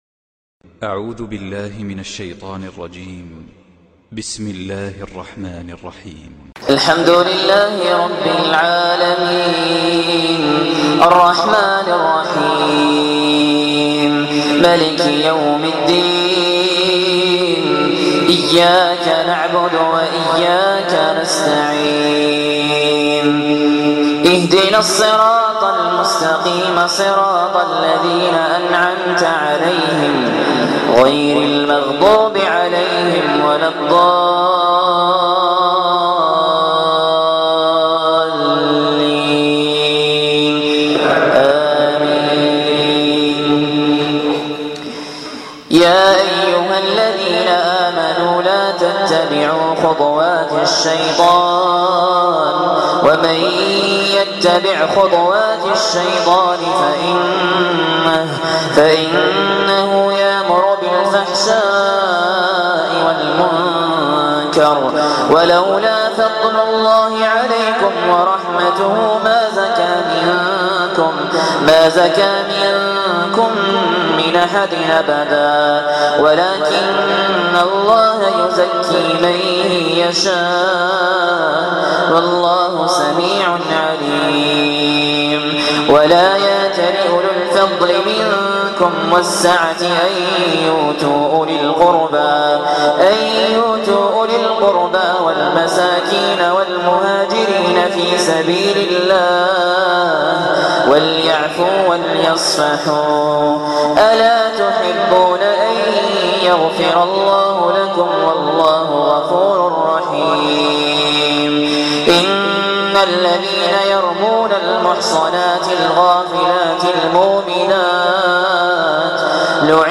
تقليد للشيخ ماهر المعيقلي تلاوة خيالية من سورة النور